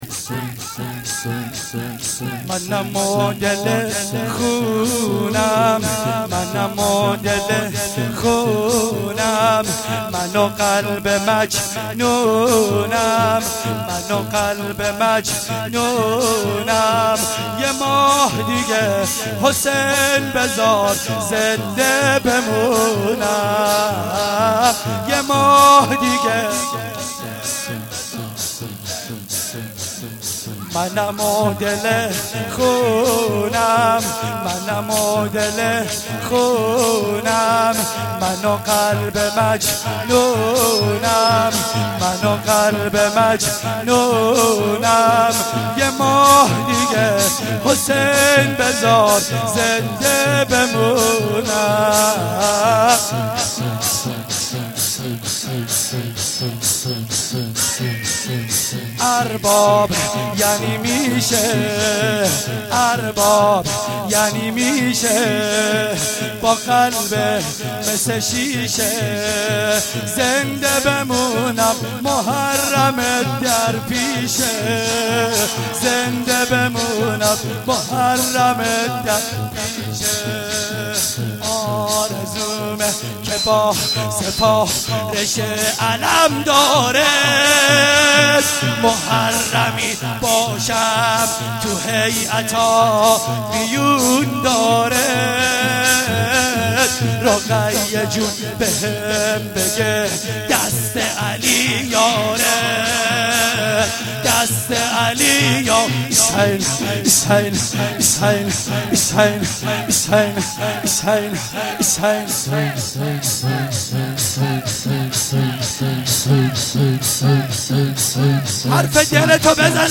شور
shoor1-Rozatol-Abbas.shahadat-emam-Bagher.mp3